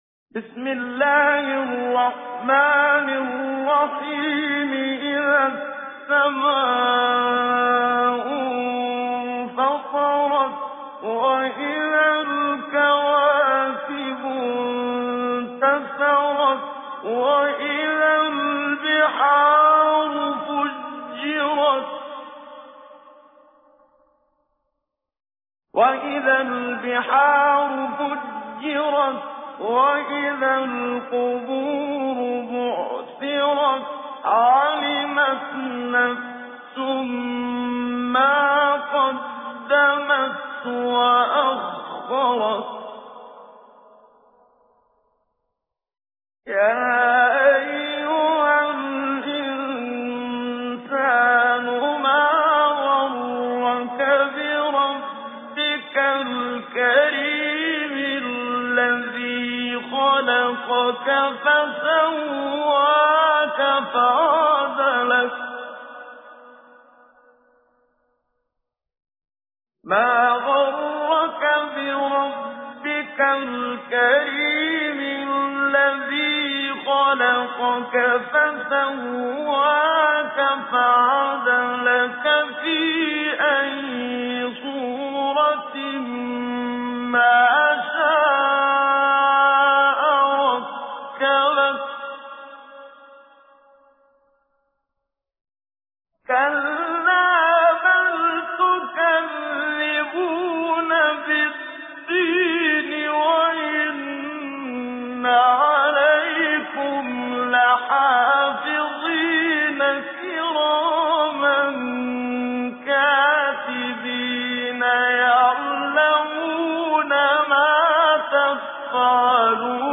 تجويد
سورة الانفطار الخطیب: المقريء الشيخ محمد صديق المنشاوي المدة الزمنية: 00:00:00